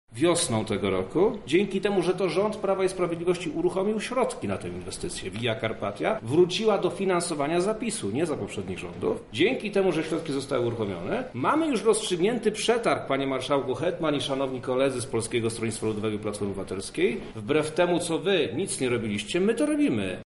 Wojewoda Przemysław Czarnek odpowiada: